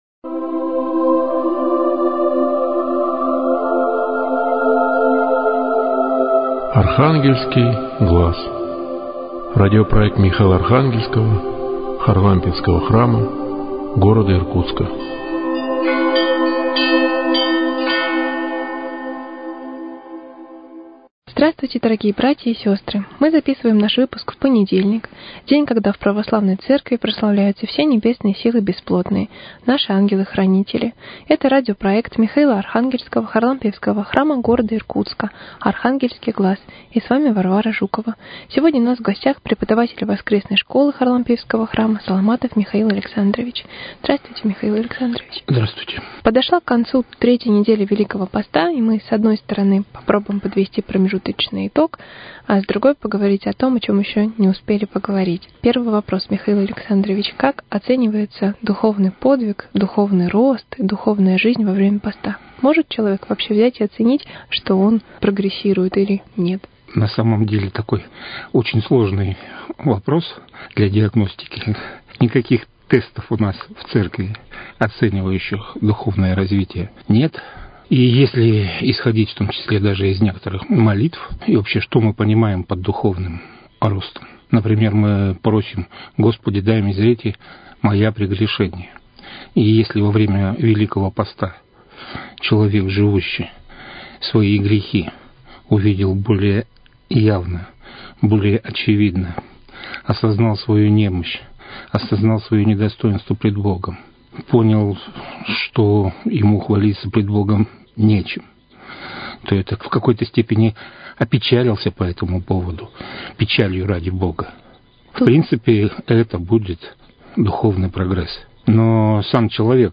Очередная передача из цикла Михаило – Архангельского Харлампиевского храма.